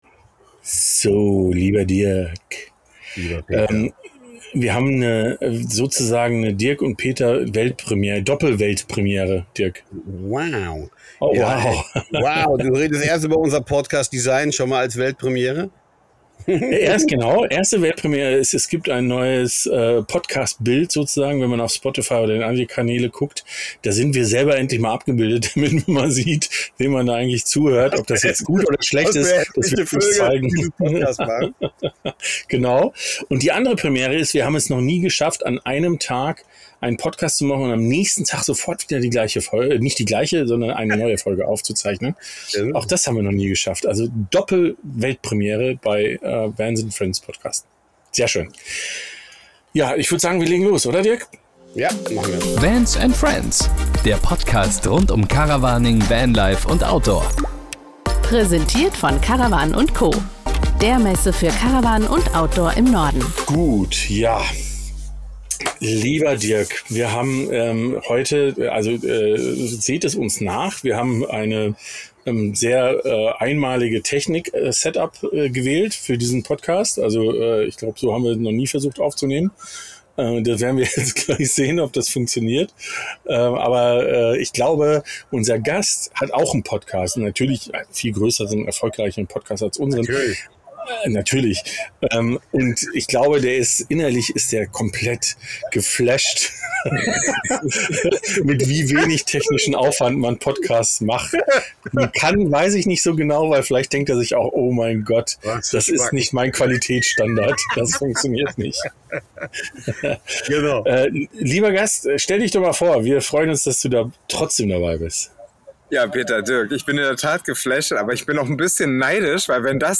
Auf jeden Fall kommen wir schnell ins Plaudern trotz einiger technischer Herausforderungen.